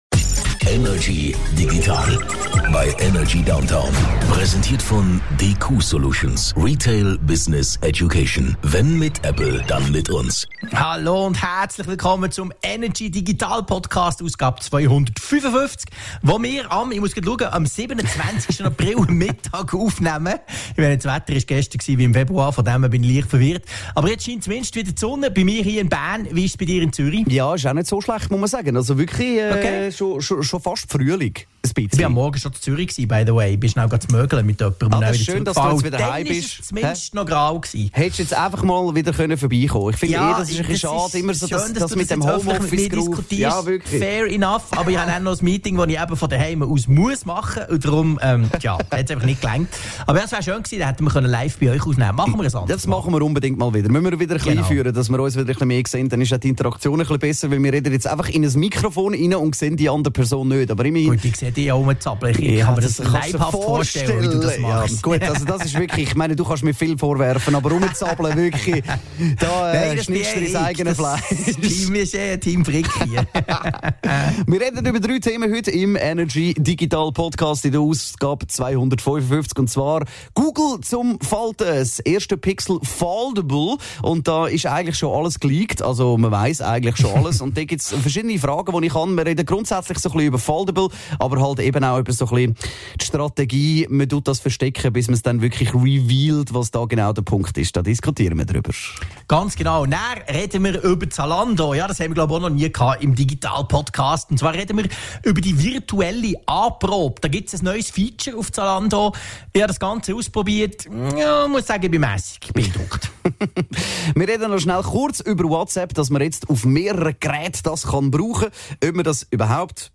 im Energy Studio
aus dem HomeOffice